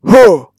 oldmanattack.mp3